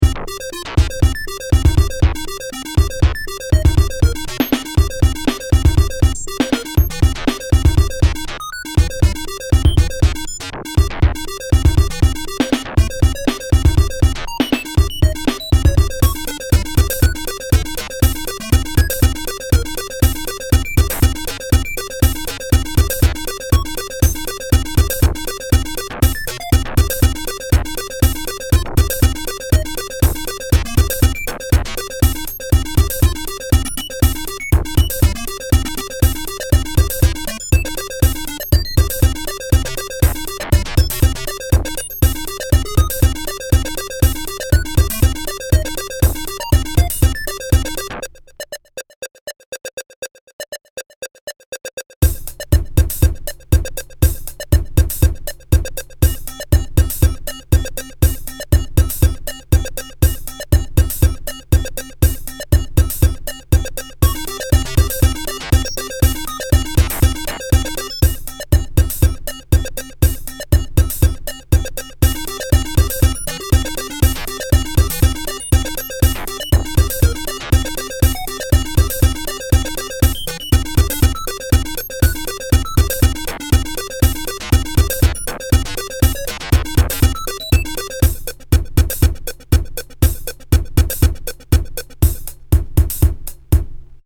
Pieza de Electro
Música electrónica
melodía
rítmico
sintetizador